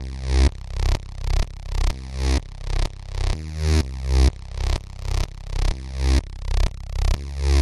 描述：房子，恍惚等的旋律合成器
Tag: 循环 恍惚间 电子乐 俱乐部 EDM 房子 舞蹈